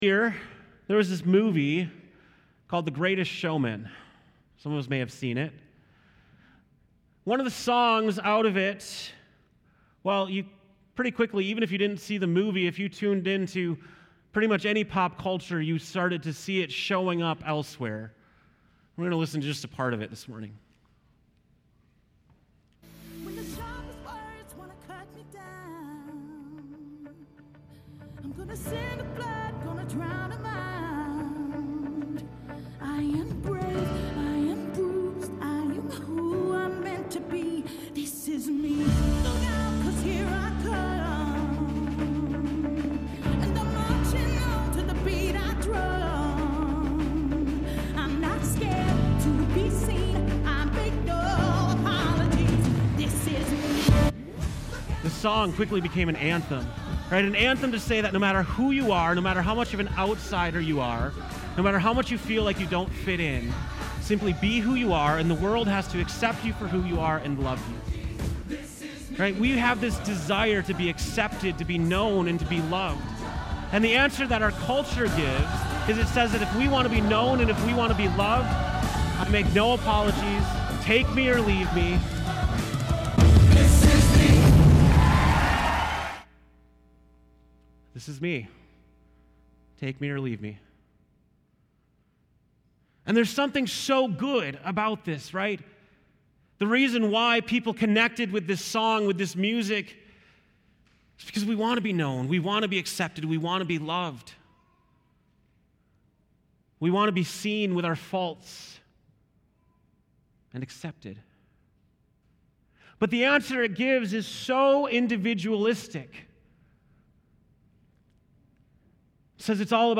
September 16, 2018 (Morning Worship)